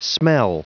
Prononciation du mot smell en anglais (fichier audio)
Prononciation du mot : smell